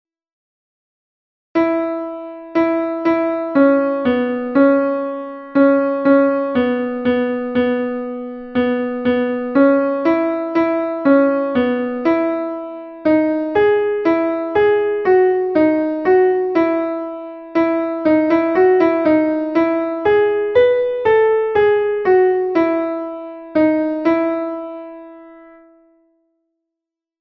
This page contains recordings of the notes for the alto voice parts for the song that will be presented during the Christmas Sunday service (December 21, 2025).
Note that blank measures for the parts are not skipped; it follows the music as written so if you hear silence that's because there's nothing written for your part in that portion of the recording (i.e., your device isn't broken and your ears still work).
Come_Thou_Alto.mp3